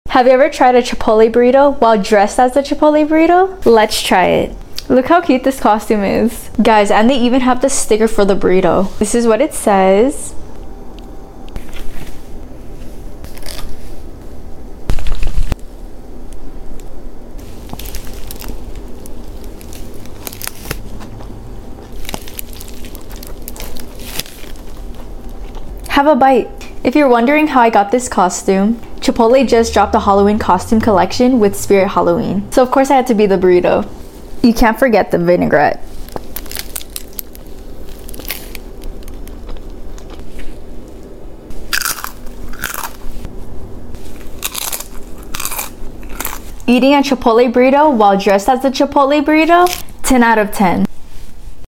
eating a Chipotle Burrito dressed sound effects free download